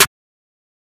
MZ Snare [Metro Bounce Hi].wav